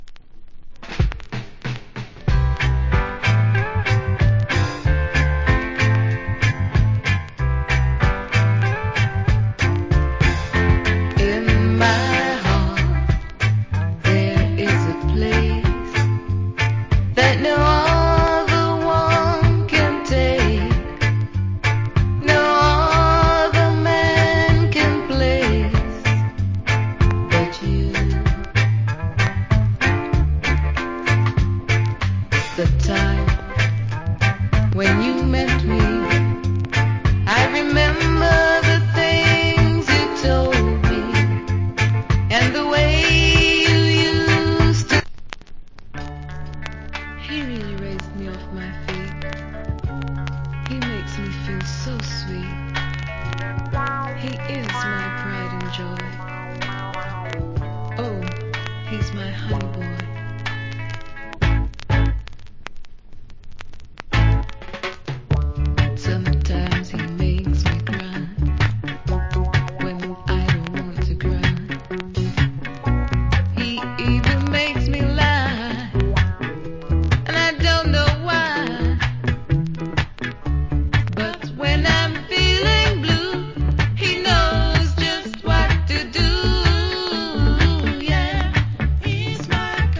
Nice Female UK Reggae Vocal.